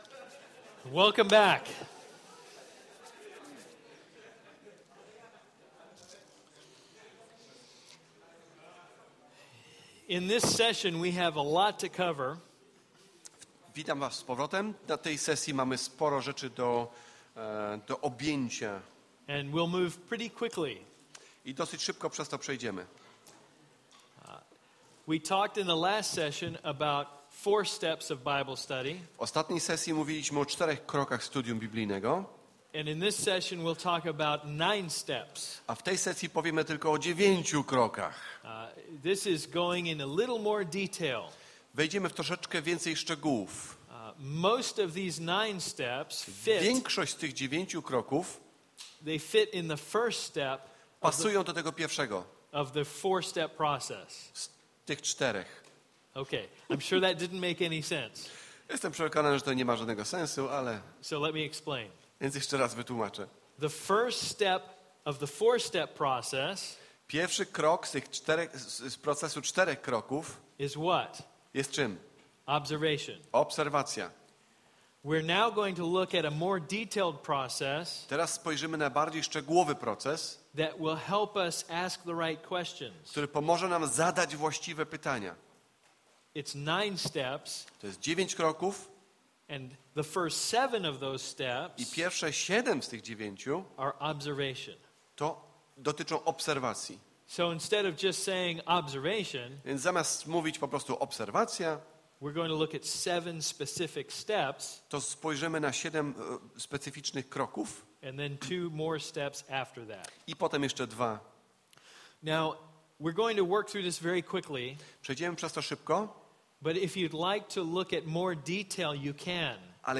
Wykład 4: Dziewięć kroków studium: 6-9